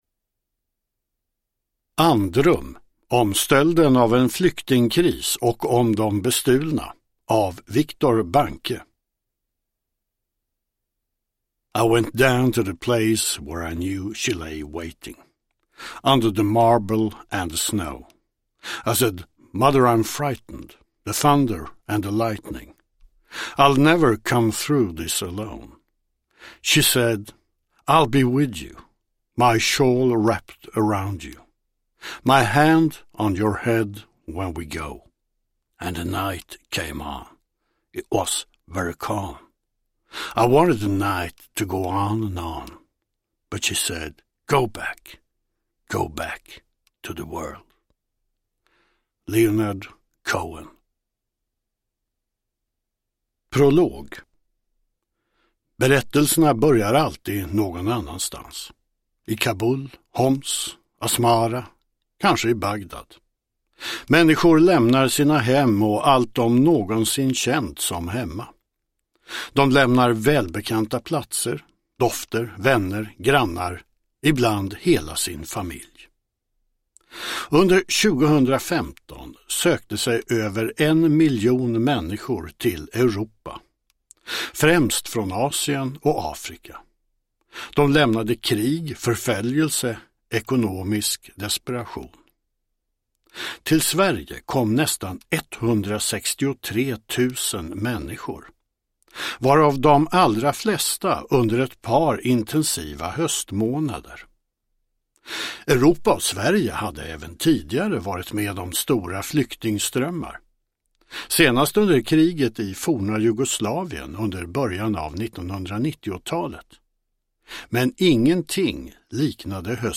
Andrum : om stölden av en flyktingkris och om de bestulna – Ljudbok – Laddas ner